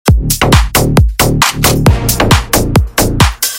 • Качество: 128, Stereo
Electronic
качающие
цикличные